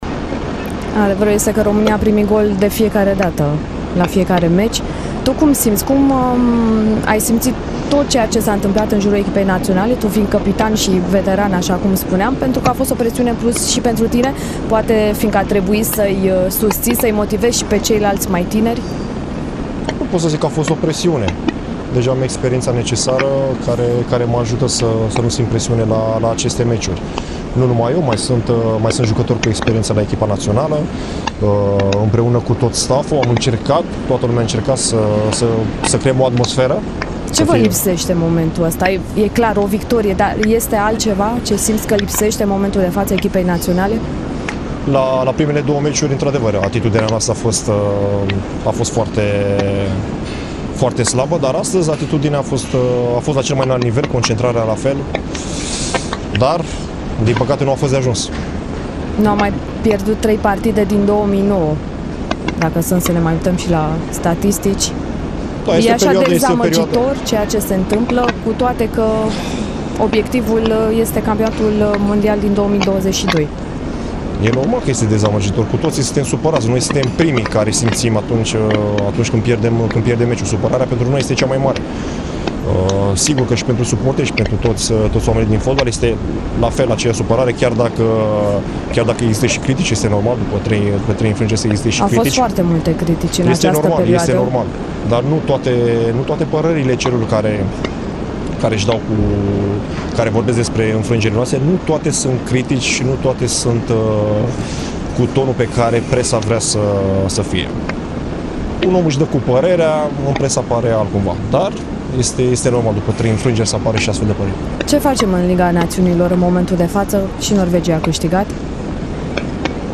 Concluzii după partida de la Ploiești au tras și doi dintre protagoniștii din teren, Ciprian Tătărușanu și Nicușor Bancu:
Tatarusanu-si-Bancu-dupa-0-1-cu-Austria.mp3